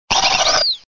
contributions)Televersement cris 4G.